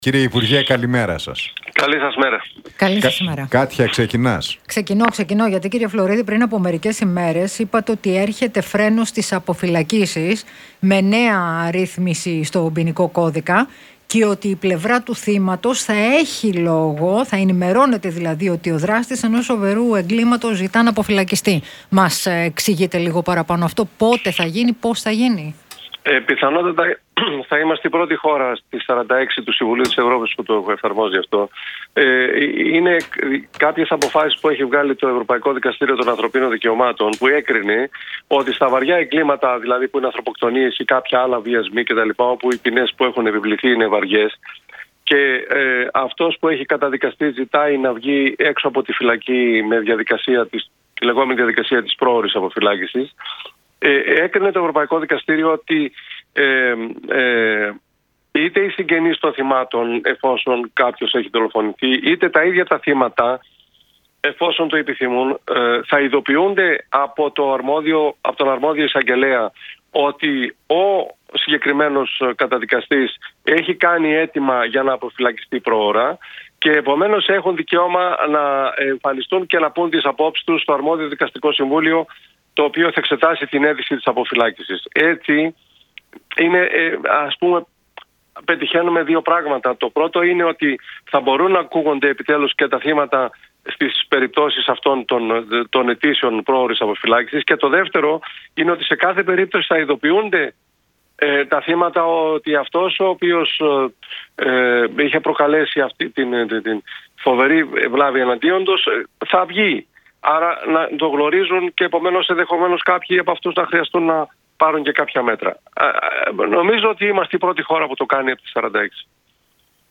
Για το σχέδιο να μπει «φρένο» στις αποφυλακίσεις μίλησε μεταξύ άλλων ο υπουργός Δικαιοσύνης, Γιώργος Φλωρίδης μιλώντας στον Realfm 97,8 και τους Νίκο Χατζηνικολάου